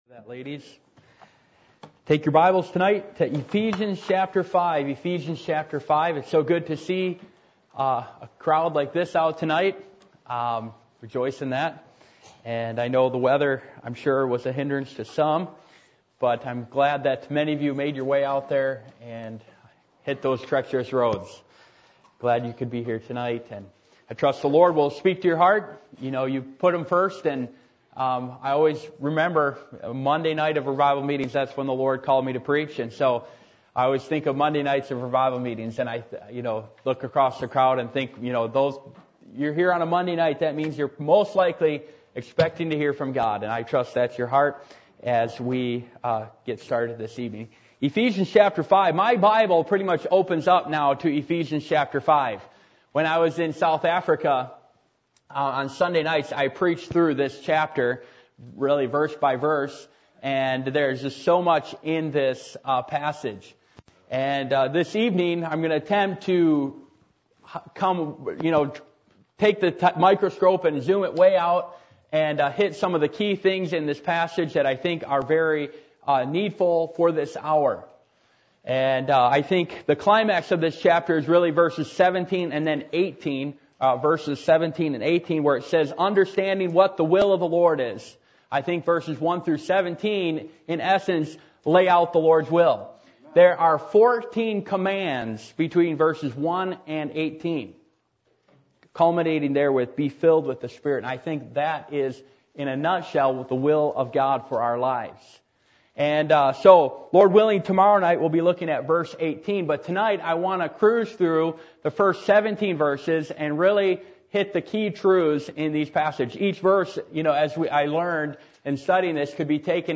Revival Meetings